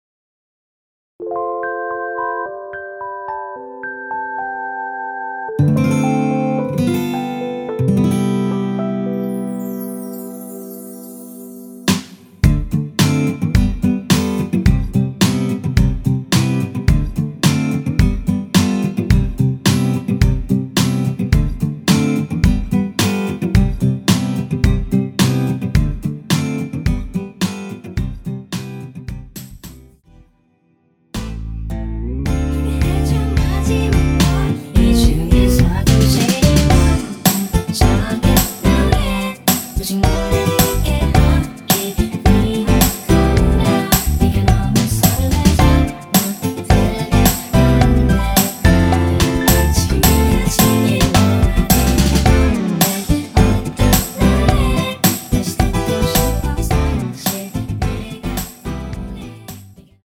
원키에서(+3)올린 코러스 포함된 MR입니다.
앞부분30초, 뒷부분30초씩 편집해서 올려 드리고 있습니다.